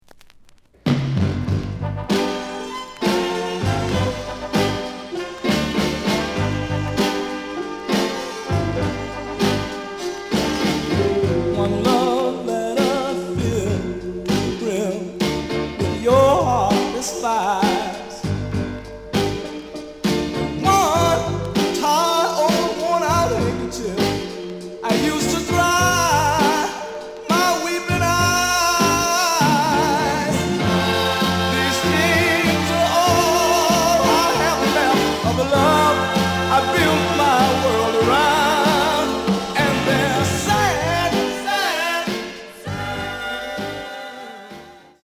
The audio sample is recorded from the actual item.
●Genre: Soul, 60's Soul
Slight cloudy on B side.